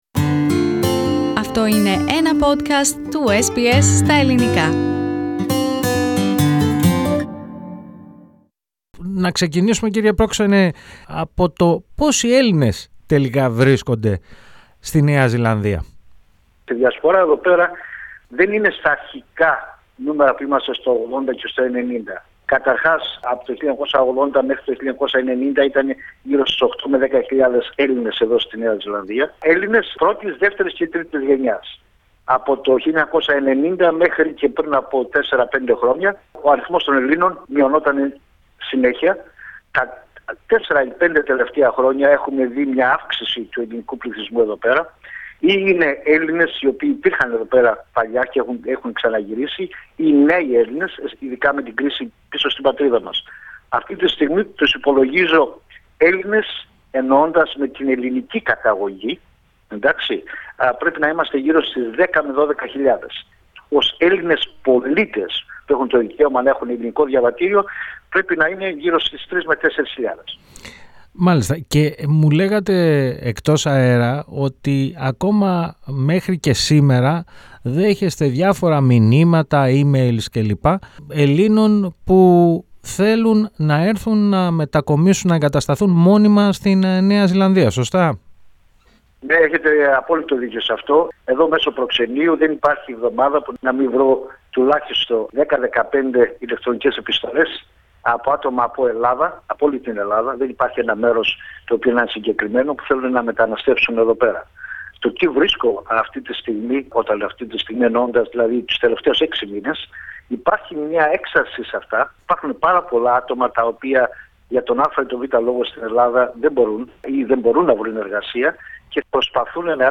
The Honorary Consul of Greece to New Zealand, George Neonakis, speaks to SBS Greek, about the Greek community of NZ and the new surge of Greek immigration.